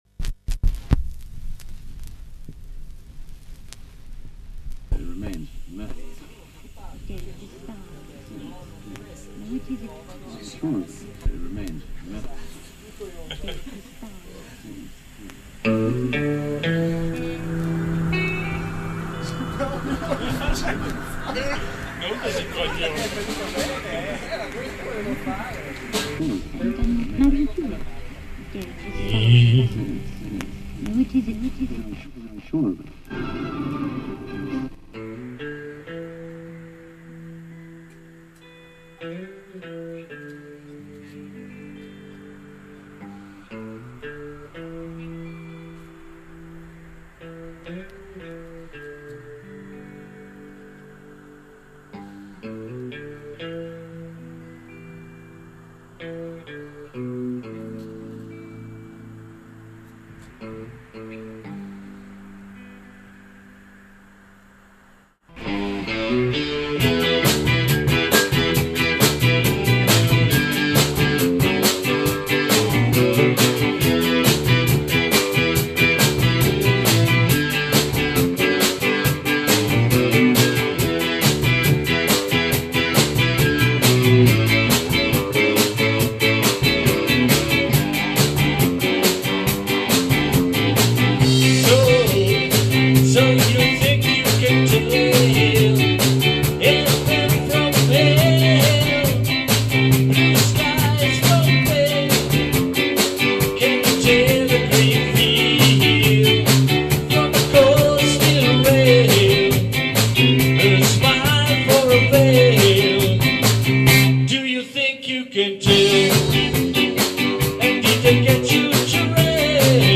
REGGAE
la registrazione del provino